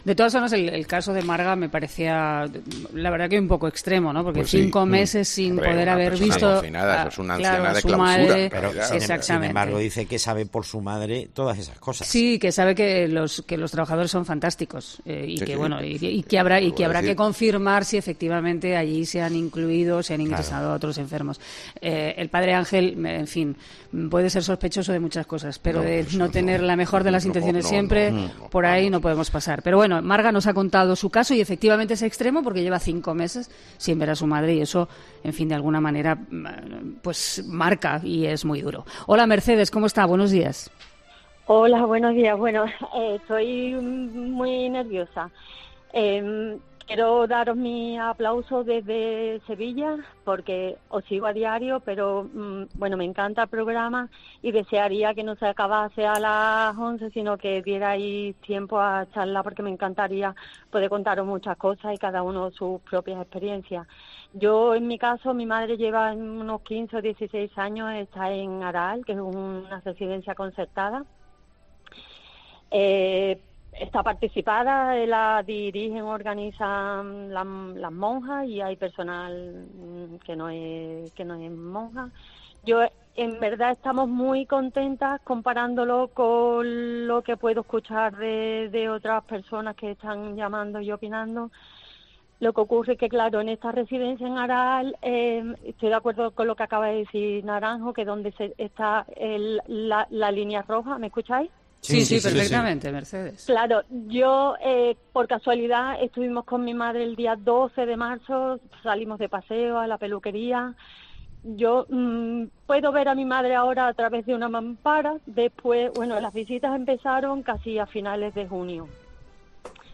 Este miércoles en 'Herrera en COPE' nos hemos acercado a las residencias para conocer la situación de nuestros abuelos en boca de sus familiares y cuidadores.